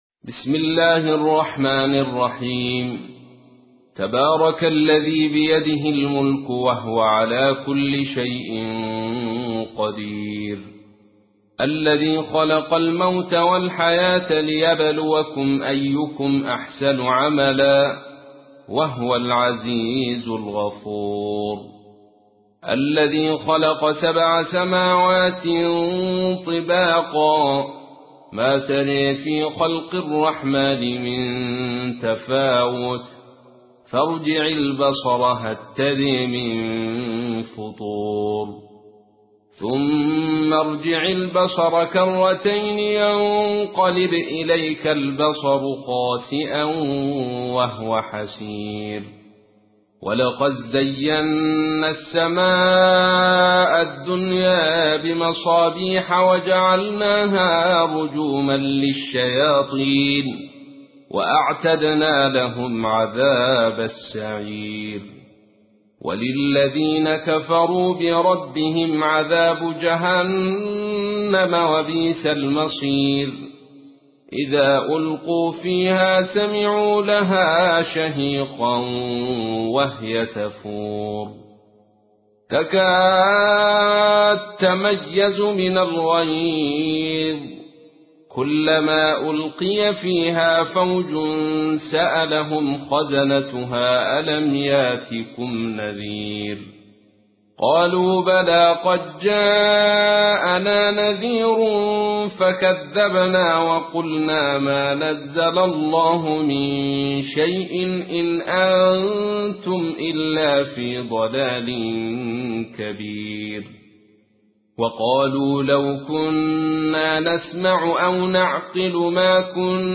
تحميل : 67. سورة الملك / القارئ عبد الرشيد صوفي / القرآن الكريم / موقع يا حسين